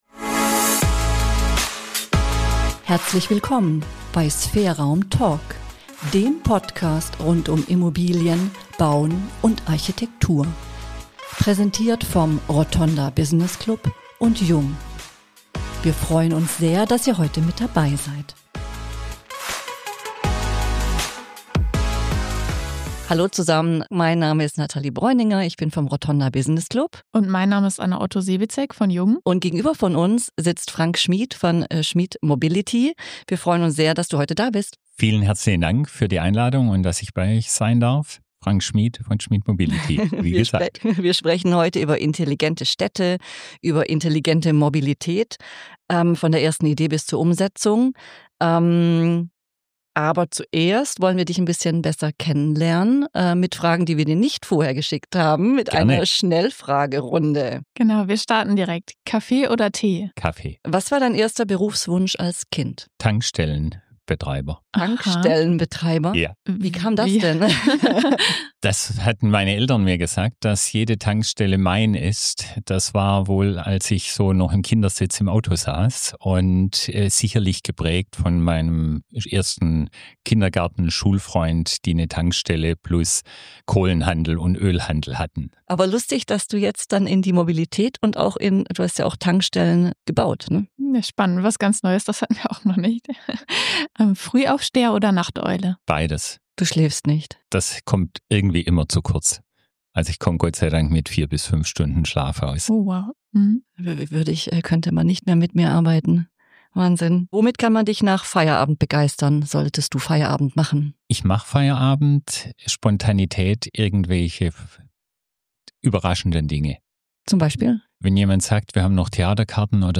Ein Gespräch über Pioniergeist, Beharrlichkeit und eine Vision, die von der Infrastruktur aus gedacht ist.